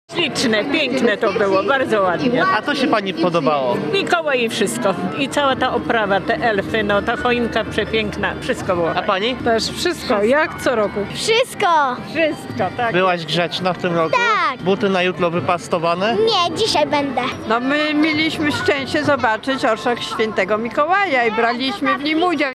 Jak podobało się mieszkańcom Gdańska? Posłuchaj: